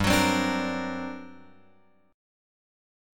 GmM11 chord {3 1 4 3 1 x} chord